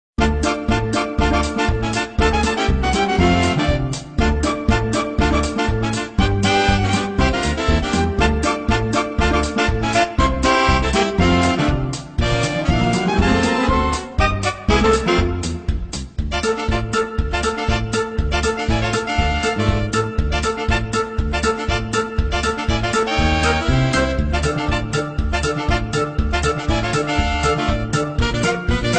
Cover
Category: Jazz Ringtones Tags